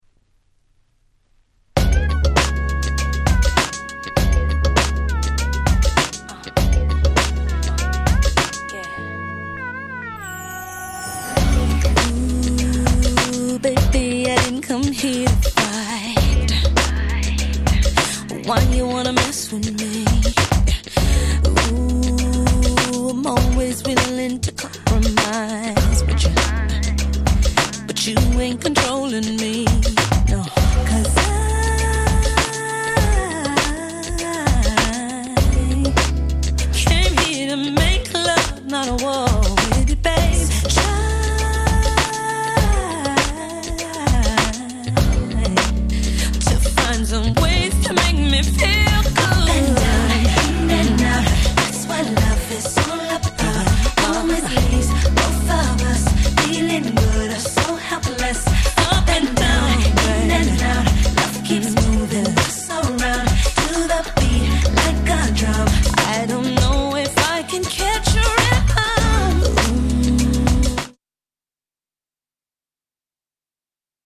08' Smash Hit R&B.